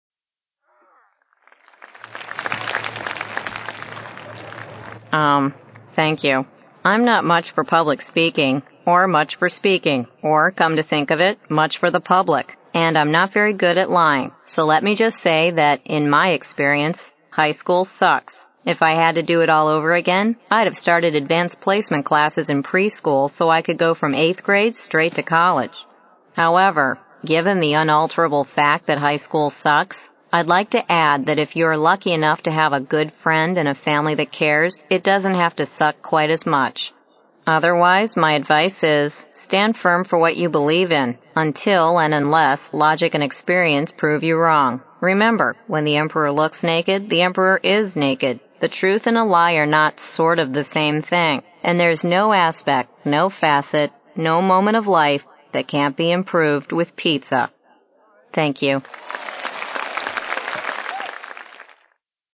Graduation speech
Category: Television   Right: Personal